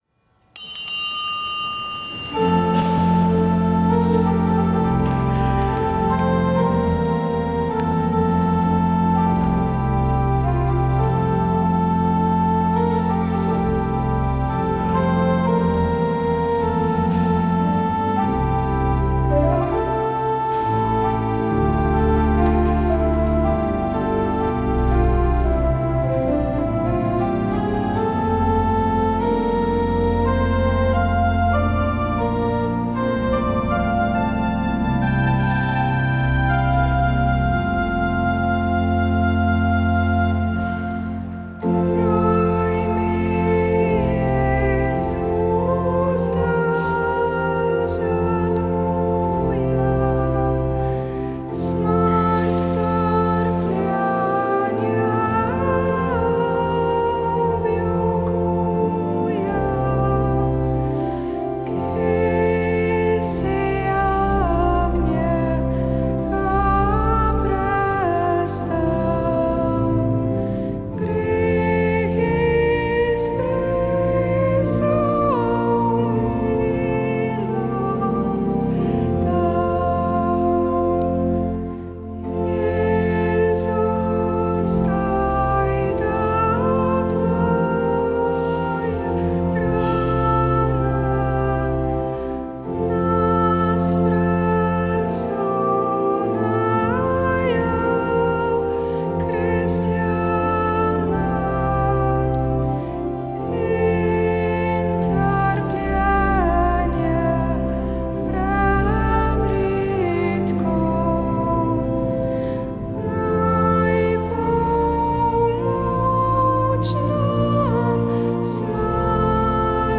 Sv. maša iz župnijske cerkve sv. Jožefa in sv. Barbare iz Idrije 29. 11.